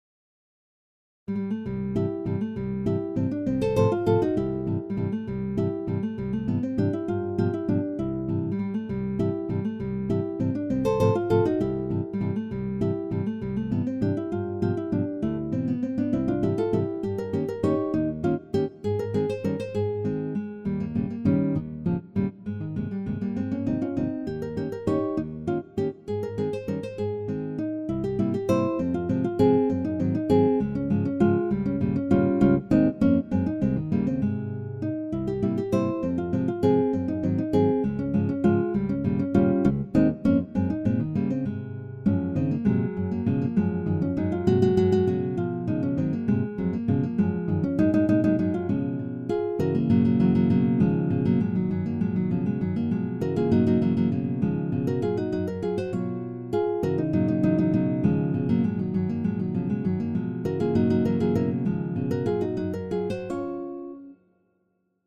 Hintergrund-Musik für Präsentationen